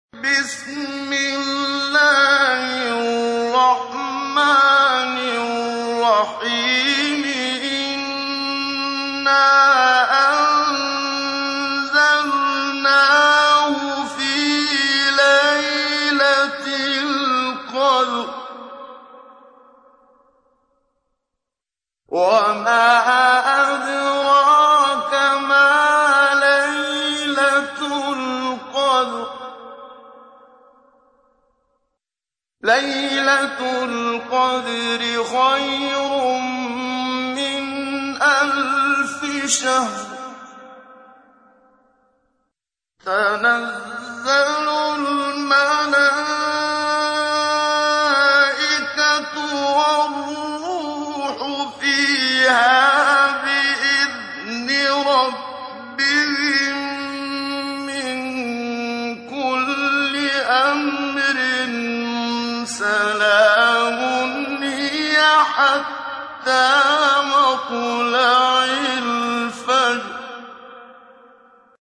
تحميل : 97. سورة القدر / القارئ محمد صديق المنشاوي / القرآن الكريم / موقع يا حسين